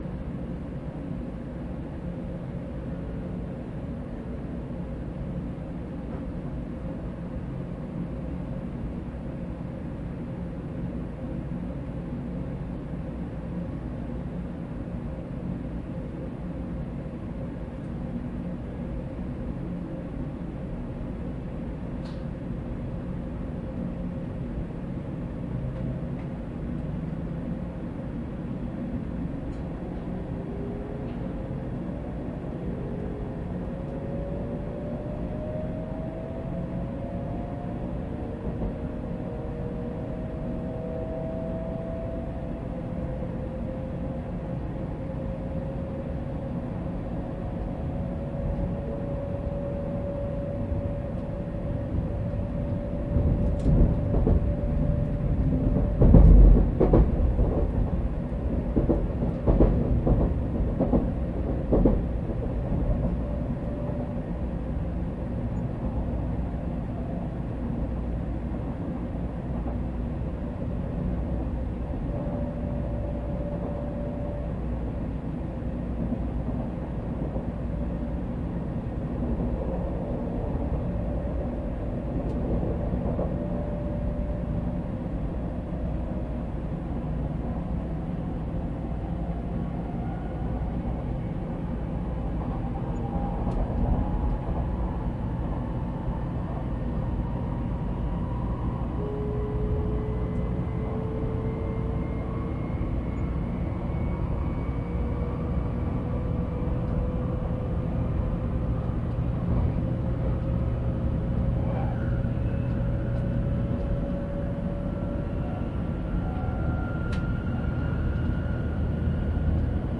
Z55編成走行音(普通客室)[N700Za.mp3/MP3-32kbps Stereo/4.14MB]
区間：山陽新幹線姫路→相生(ひかり473号) New!
種類：VVVFインバータ制御(東芝系？3レベルIGBT、1C4M)、WNカルダン駆動